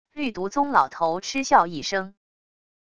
绿毒宗老头嗤笑一声wav音频